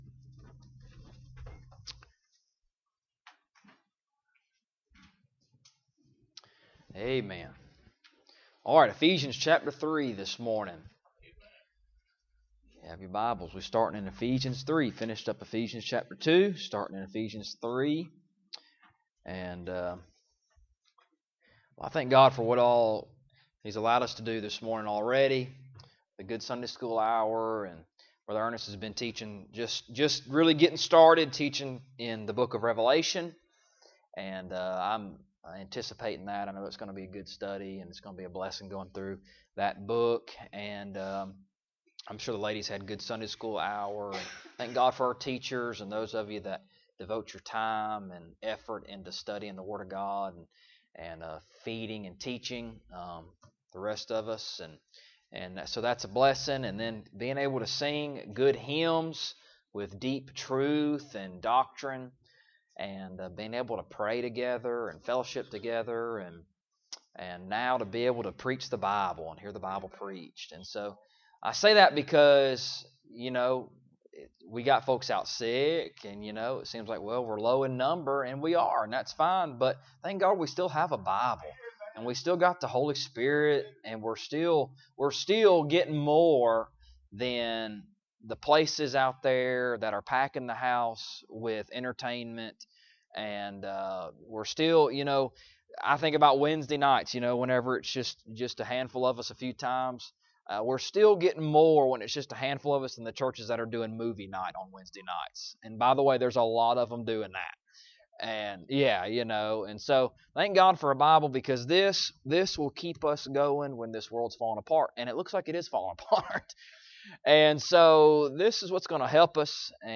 Ephesians Passage: Ephesians 3:1-7 Service Type: Sunday Morning Topics